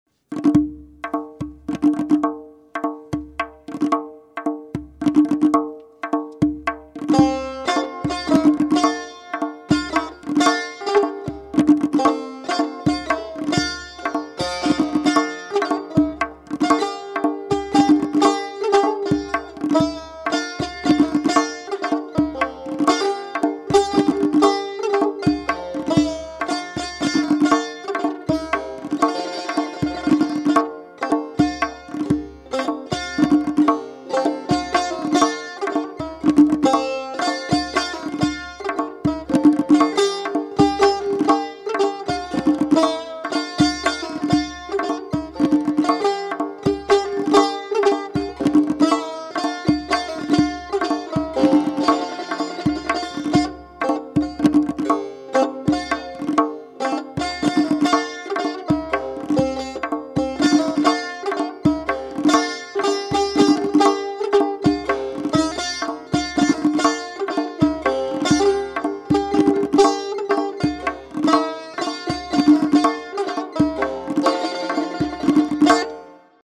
آموزش همنوازی تنبک با آهنگ- درس سوم
آموزش آهنگ داش داش برای تنبک
آهنگی که برای همنوازی در این درس در نظر گرفته ایم یک ملودی شش و هشت بسیار شاد و تا حدودی روحوضی به نام داش داش داشم من از مرحوم جلال همتی است.